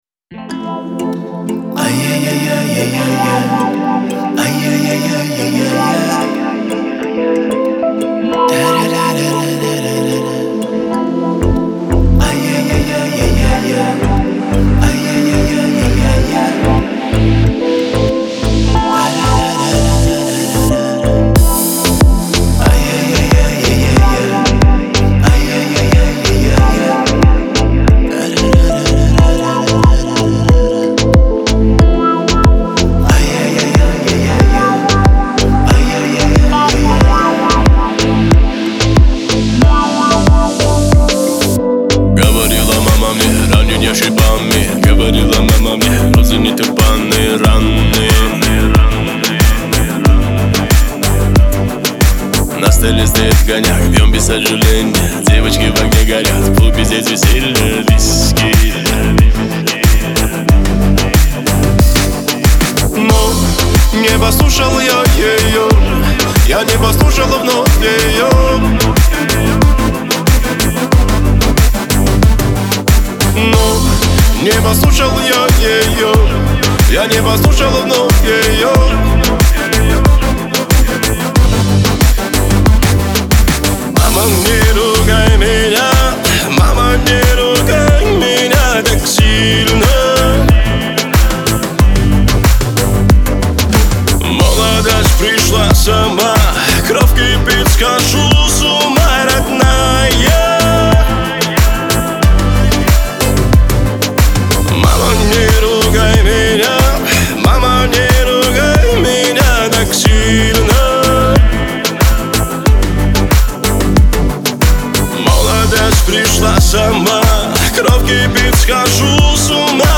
это трек в жанре поп с элементами фолка